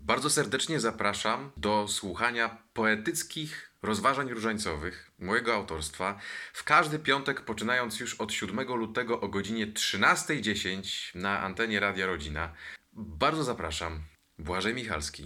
Zaproszenie autora